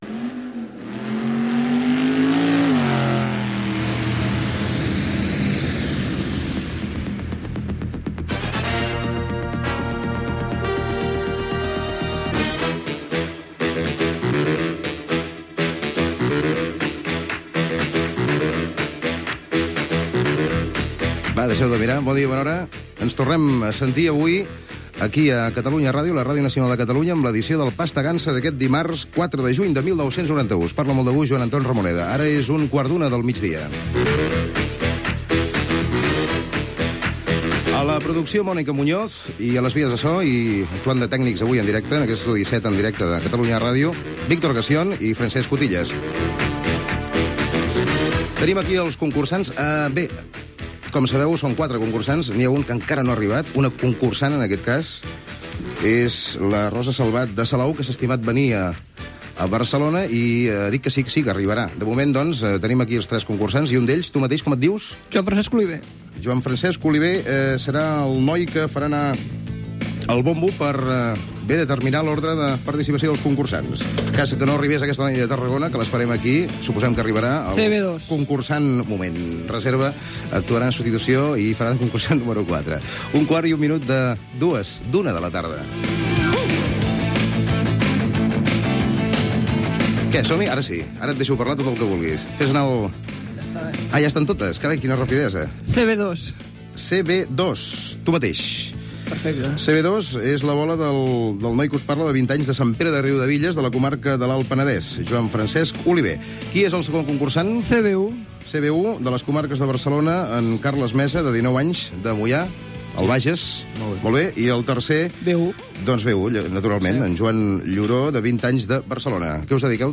Sintonia, data, hora, equip, sorteig de l'ordre d'intervenció dels concursants. Primeres preguntes del concurs
Entreteniment
FM